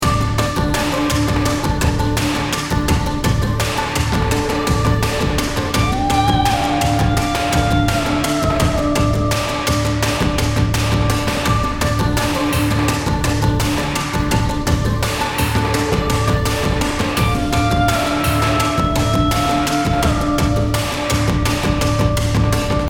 528hz BPM80-89 Game Instrument Soundtrack インストルメント
BPM 84